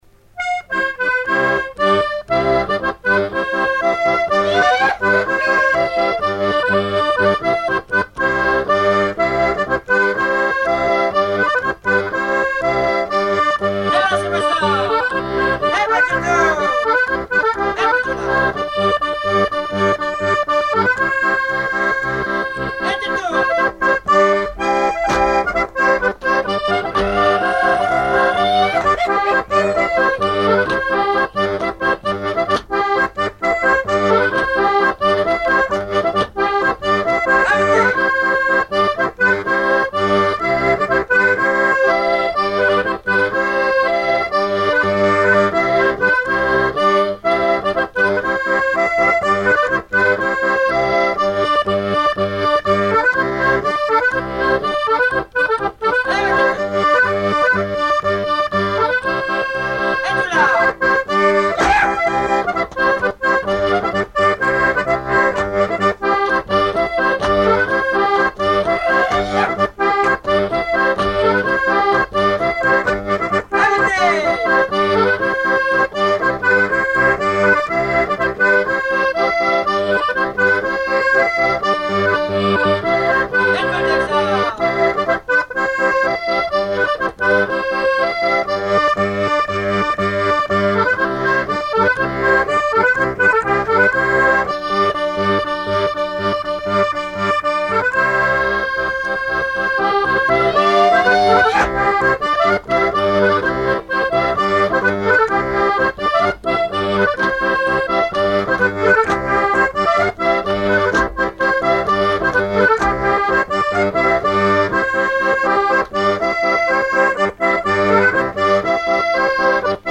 danse : ronde : grand'danse
Chansons traditionnelles
Pièce musicale inédite